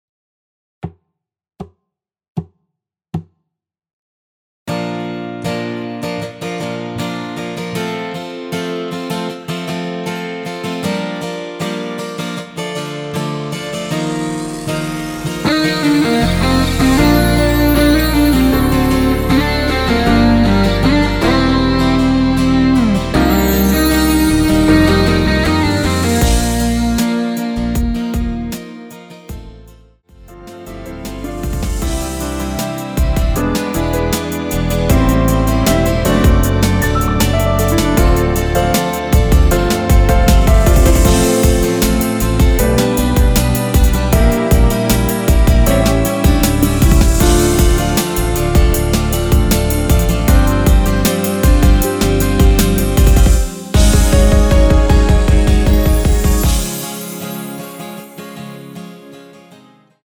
전주 없이 시작하는 곡이라서 노래하기 편하게 카운트 4박 넣었습니다.(미리듣기 확인)
원키에서(-6)내린 MR입니다.
Bb
앞부분30초, 뒷부분30초씩 편집해서 올려 드리고 있습니다.
중간에 음이 끈어지고 다시 나오는 이유는